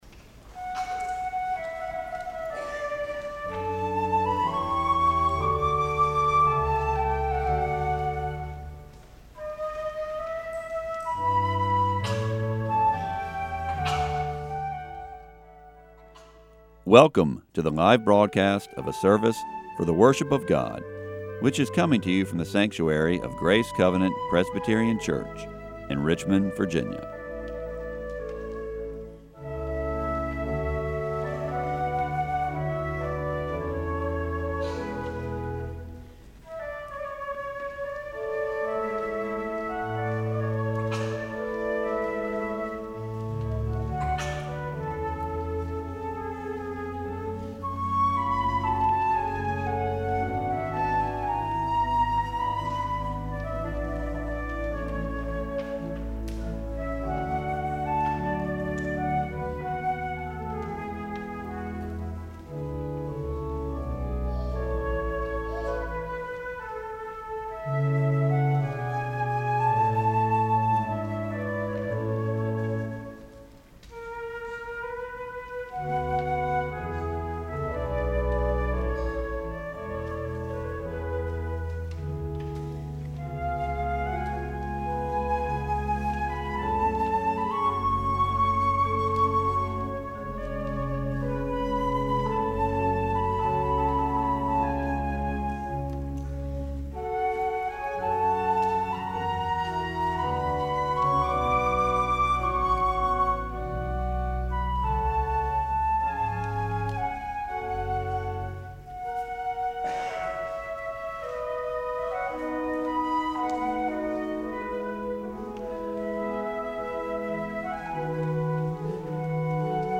flute
organ